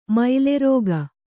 ♪ maile rōga